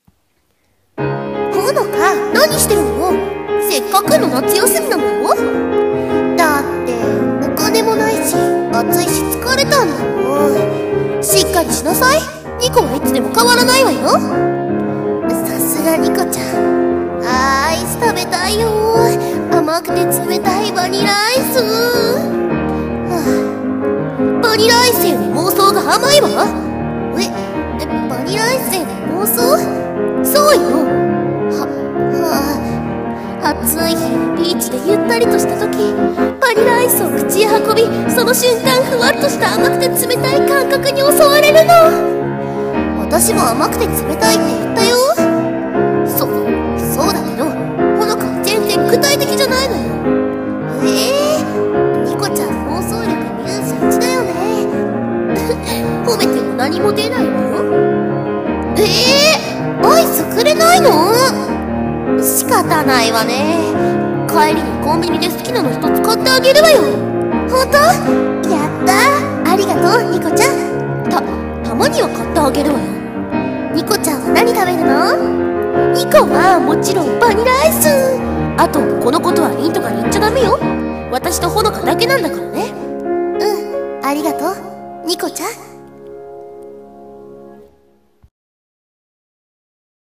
声劇台本 にこと穂乃果のSummer vacation